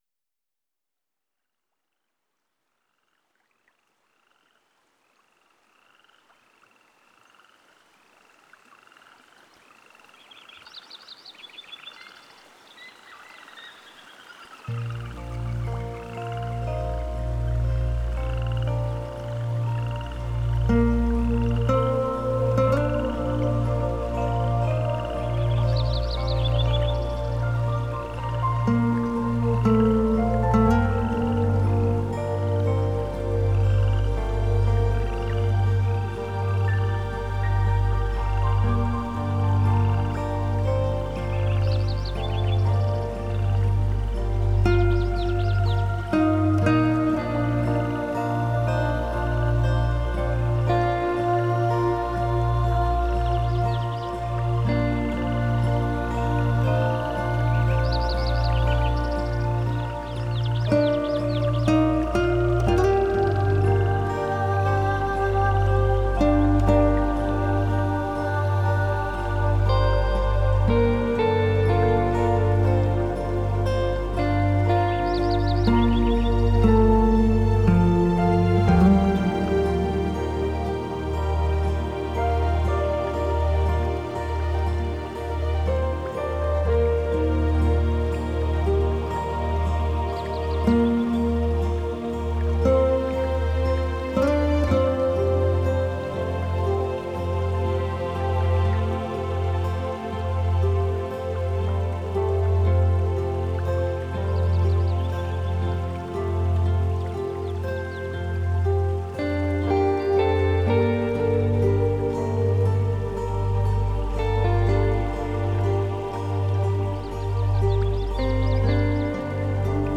New age Релакс Медитативная музыка Нью эйдж Музыка релакс